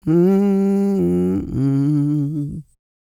E-SOUL 305.wav